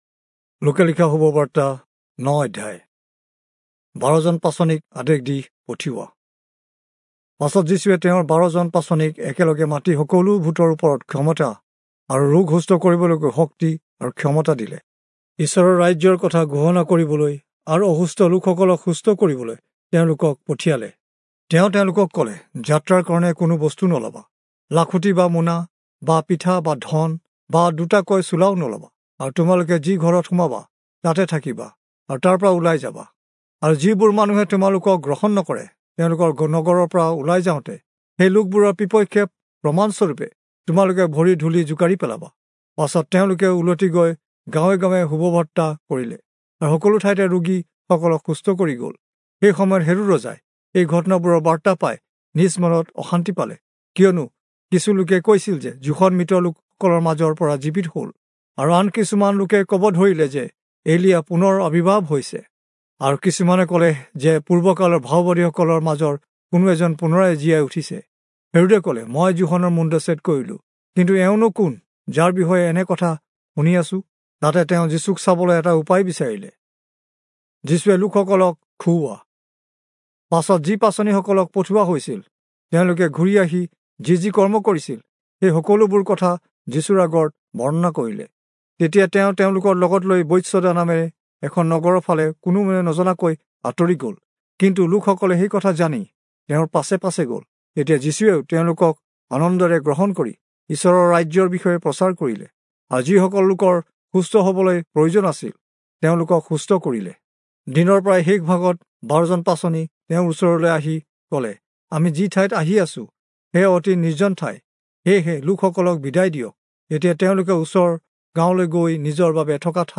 Assamese Audio Bible - Luke 21 in Hcsb bible version